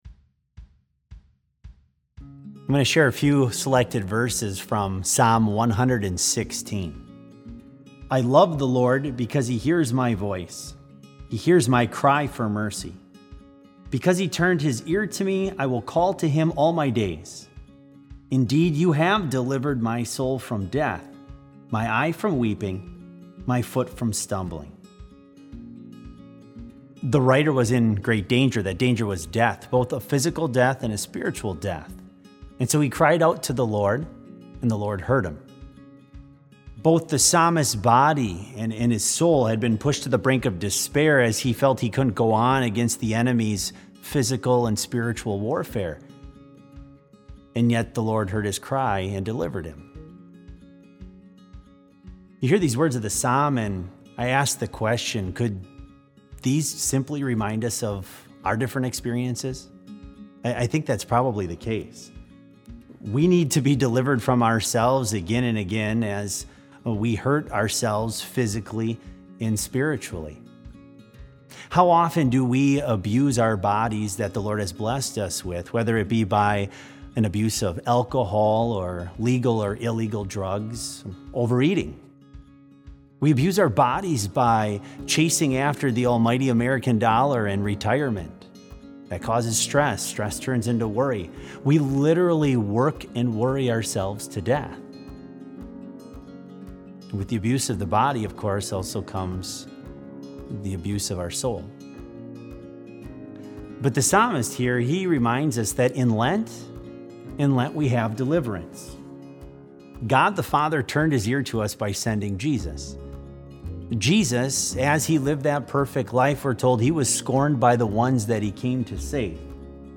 Complete Service
This Special Service was held in Trinity Chapel at Bethany Lutheran College on Tuesday, March 31, 2020, at 10 a.m. Page and hymn numbers are from the Evangelical Lutheran Hymnary.